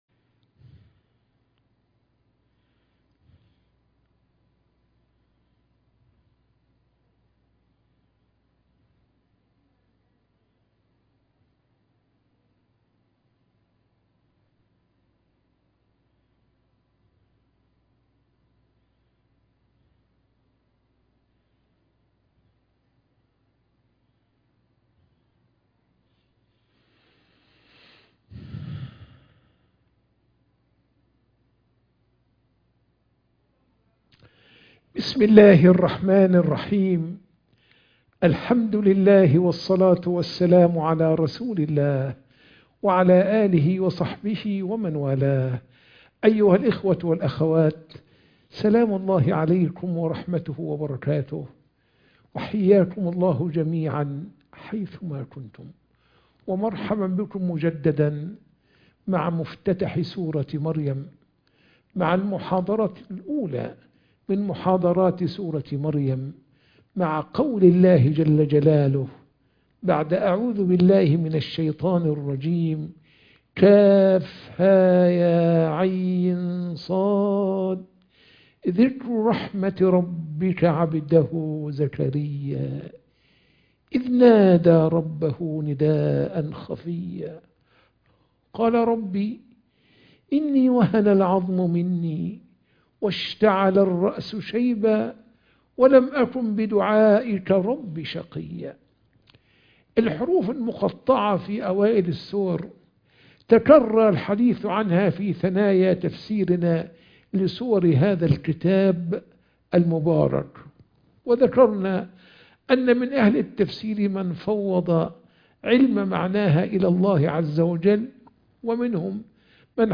محاضرة التفسير - سورة مريم - المحاضرة 1